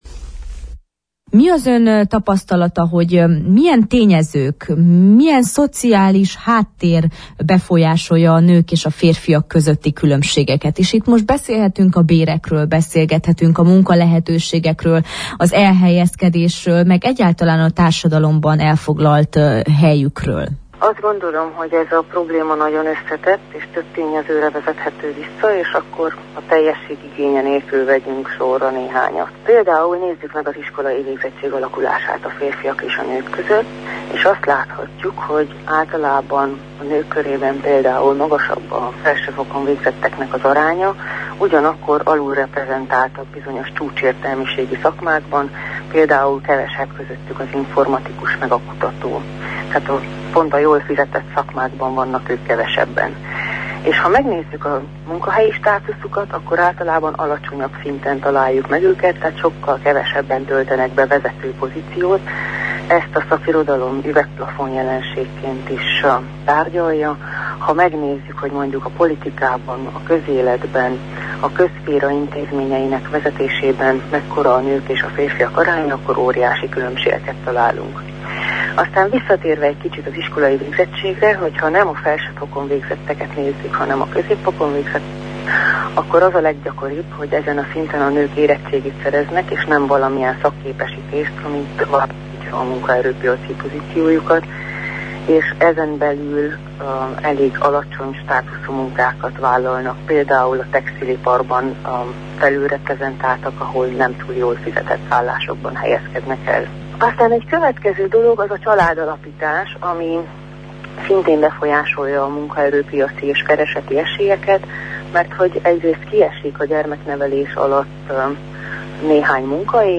szociológust kérdeztük.